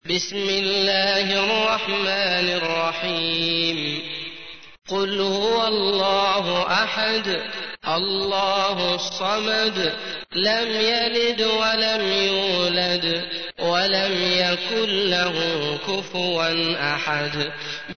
تحميل : 112. سورة الإخلاص / القارئ عبد الله المطرود / القرآن الكريم / موقع يا حسين